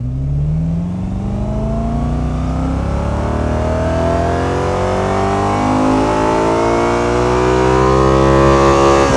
v8_02_Accel.wav